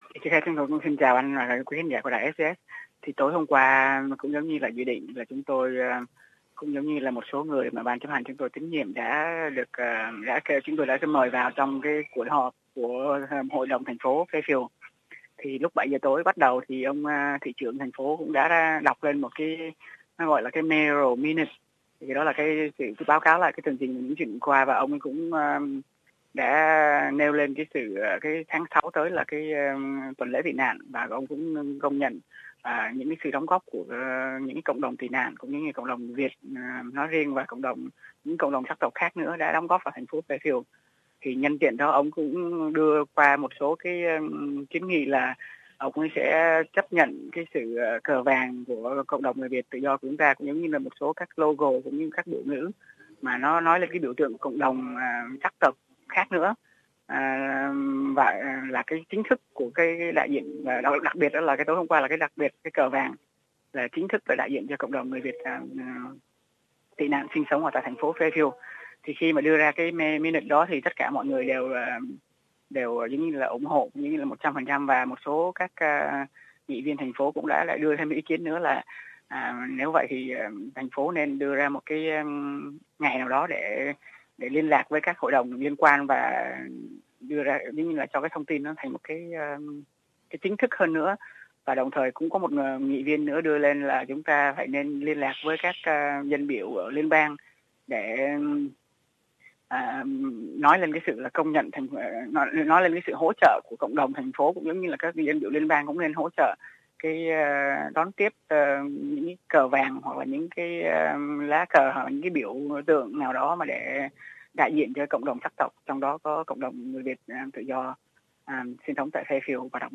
SBS phỏng vấn